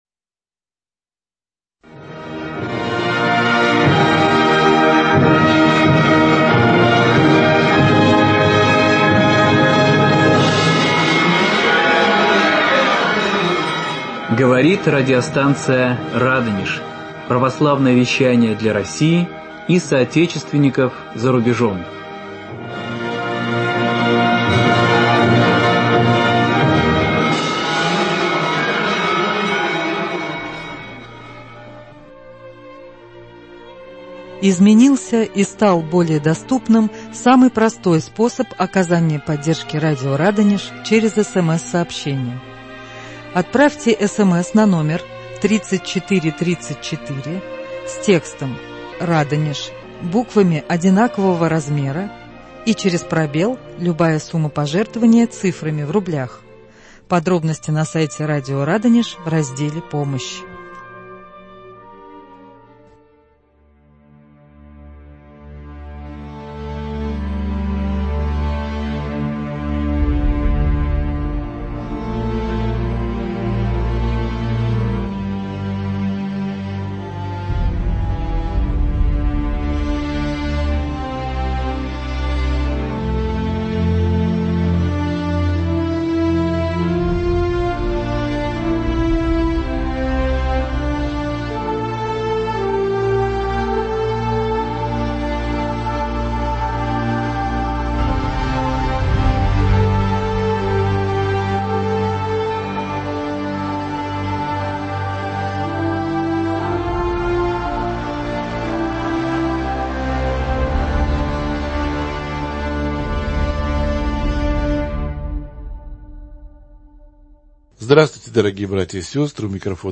В студии радио
Программа иллюстрирована звуковым материалом.